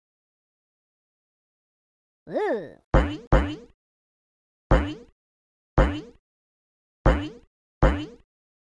Bounce Sound Effect sound effects free download